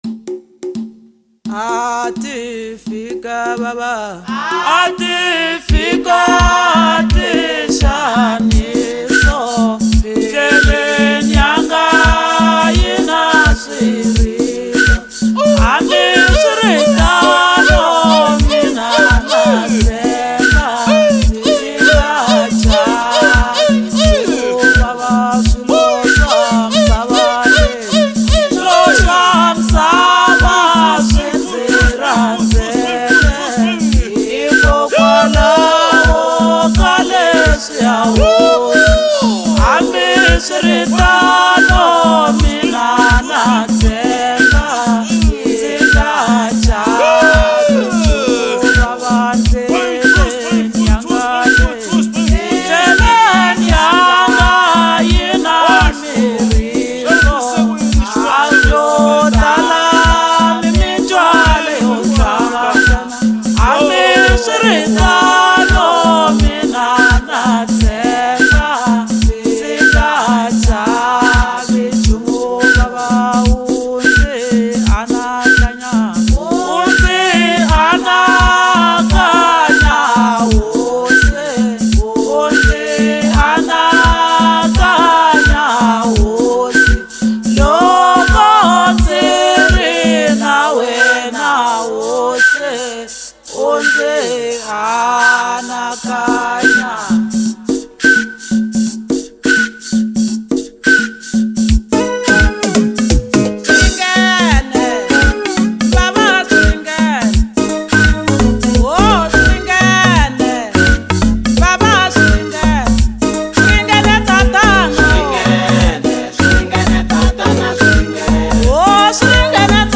Home » Gospel » Hip Hop